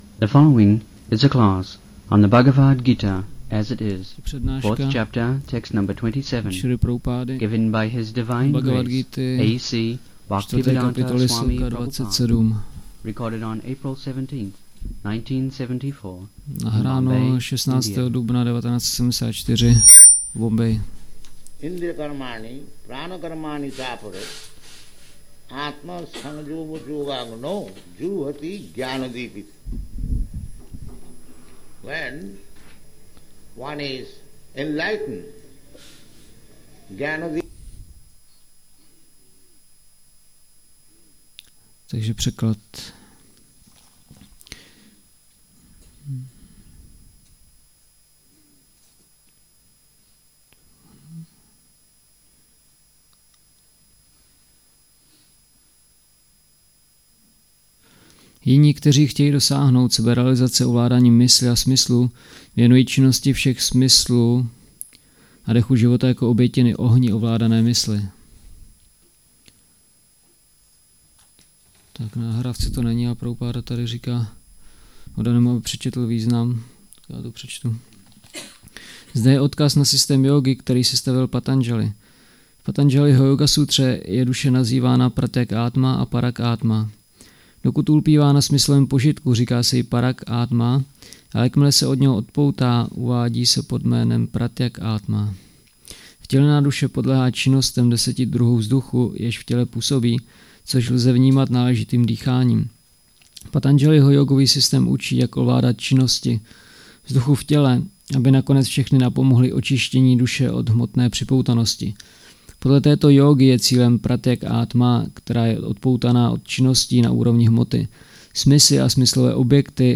1974-04-16-ACPP Šríla Prabhupáda – Přednáška BG-4.27 Bombay